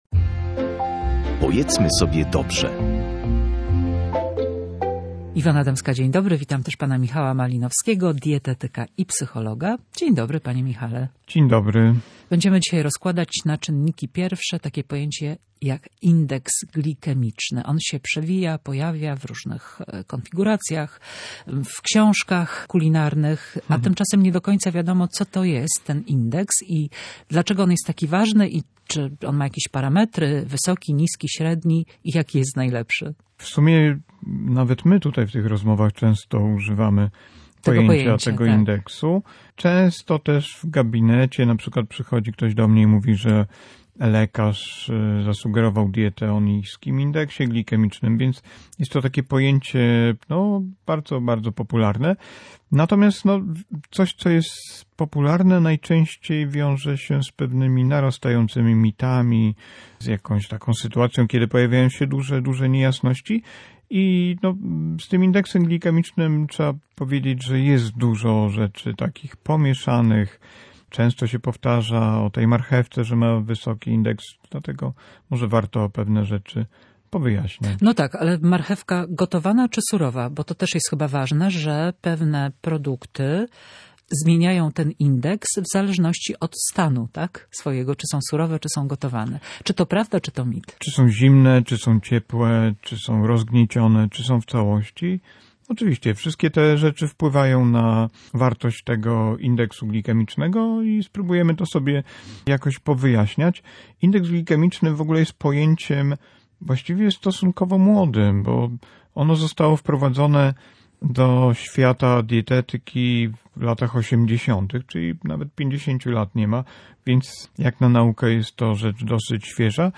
Wyjaśnia specjalista